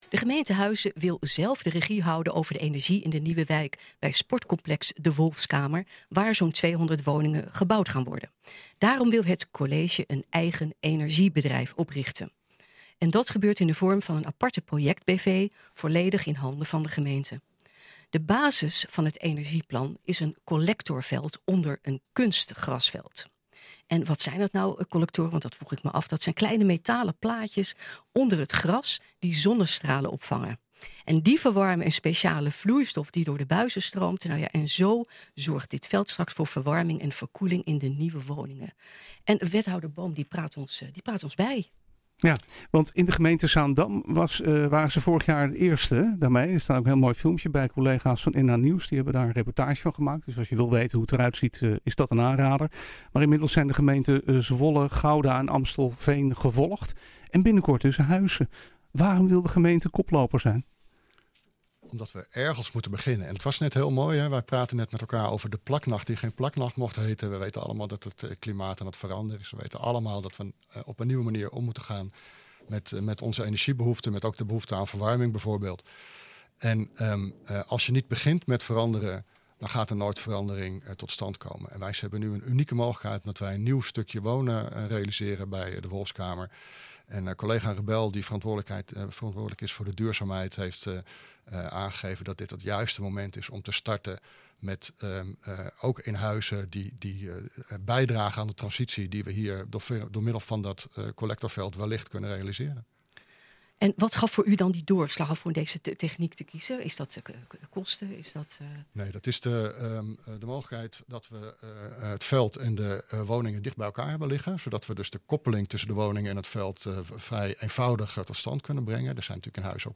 Wethouder Boom praat ons bij.